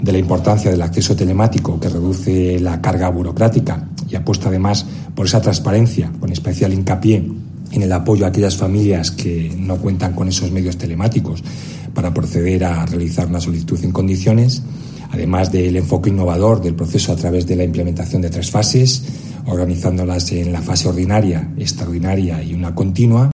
Declaraciones del director general de Centros Docentes de la Generalitat, Jorge Cabo